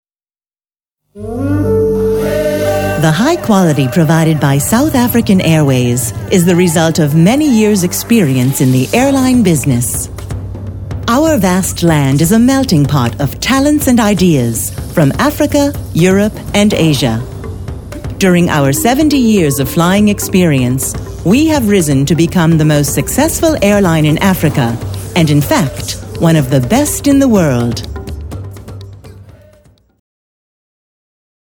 Sehr vielseitige Stimme - von warm bis hell; von mittel-tief bis hoch.
englische Sprecherin.
Sprechprobe: Werbung (Muttersprache):
English (US), female voiceover artist.